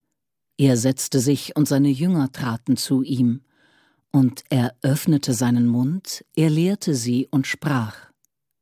Die Hörbibel - Einheitsübersetzung (Hörbuch/Hörspiel - CD)
• Kardinal Rainer Maria Woelki (Sprecher)